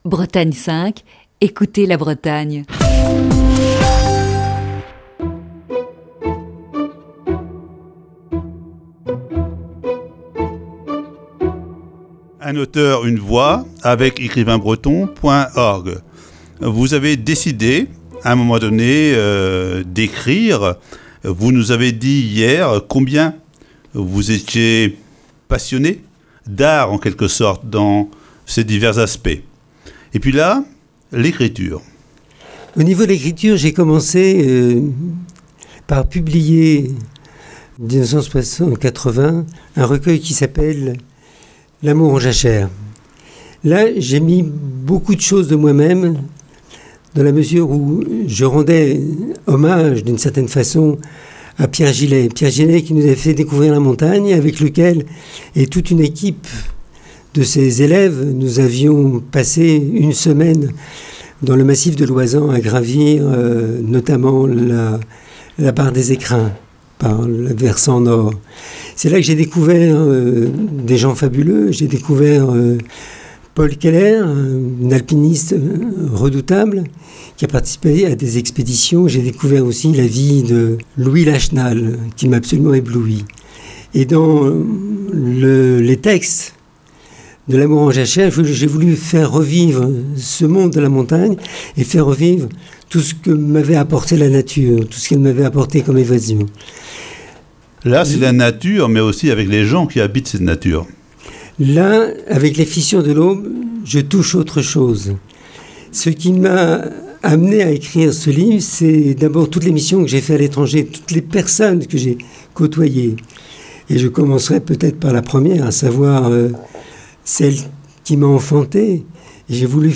Voici ce jeudi, la quatrième partie de cette série d'entretiens.